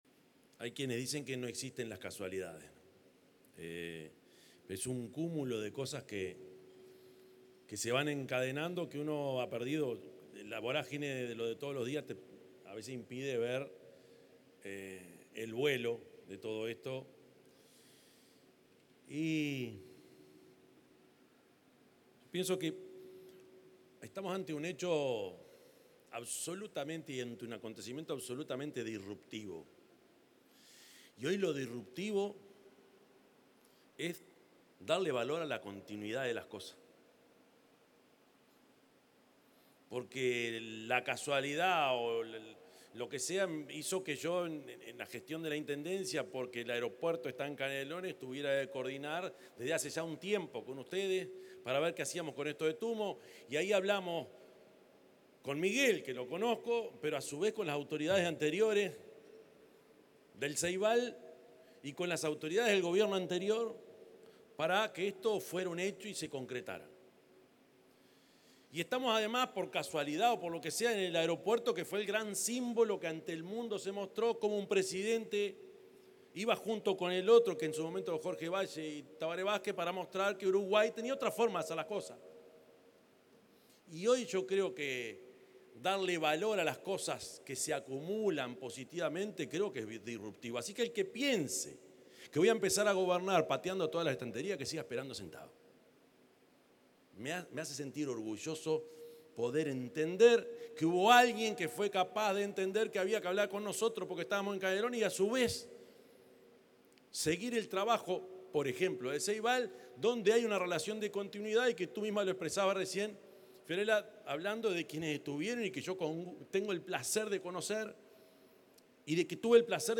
Palabras del presidente Yamandú Orsi en la presentación del centro Tumo
Durante la presentación del primer centro educativo Tumo en Uruguay y la colocación de la piedra fundamental, se expresó el presidente de la República